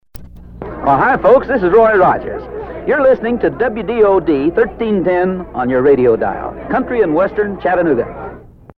The famous cowboy did the liner in one take.
I did preserve the station identification recorded on my small cassette recorder, which was a Christmas present from my dad.